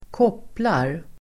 Uttal: [²k'åp:lar]